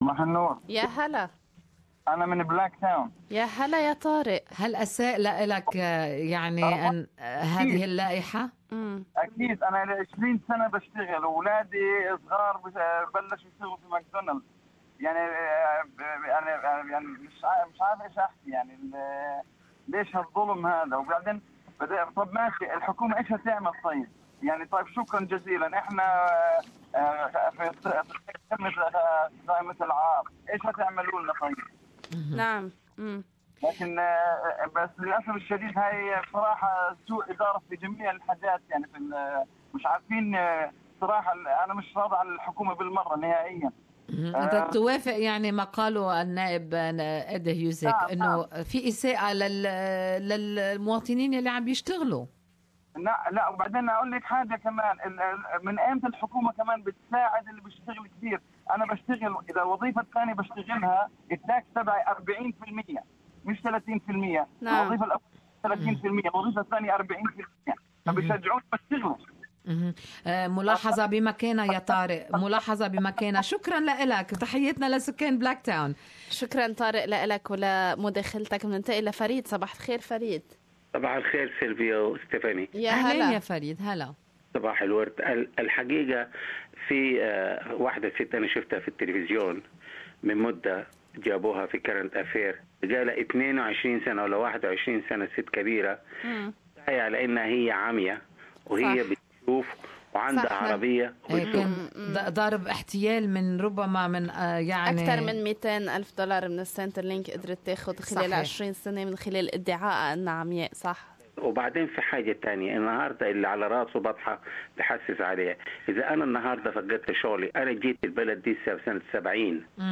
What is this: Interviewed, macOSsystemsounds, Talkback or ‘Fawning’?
Talkback